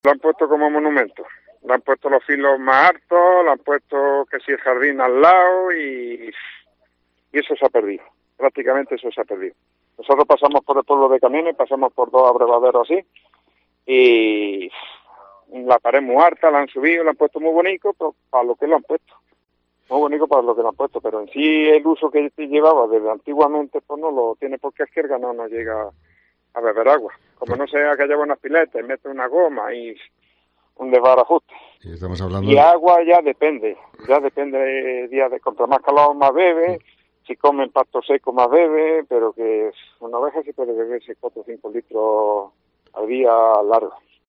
pastor afectado por la sequía